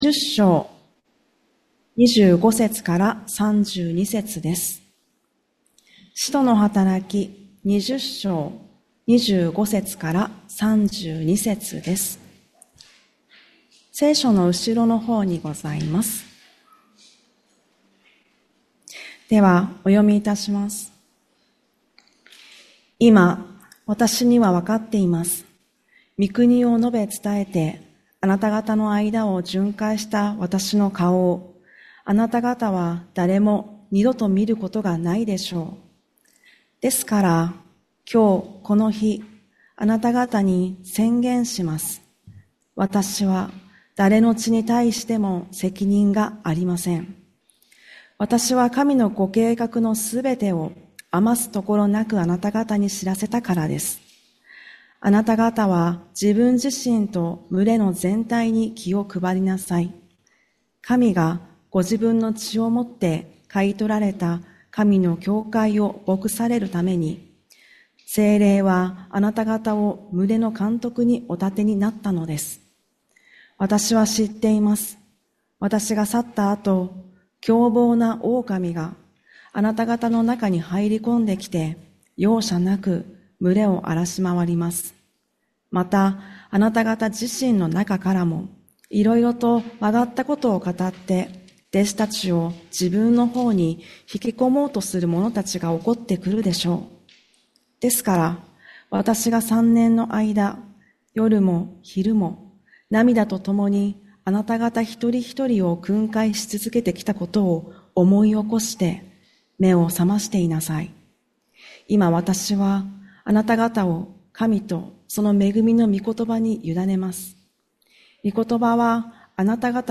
… continue reading 18 حلقات # フィットネス # キリスト教 # メンタルヘルス # 自治医大前キリスト教会 # 礼拝メッセージ キリスト教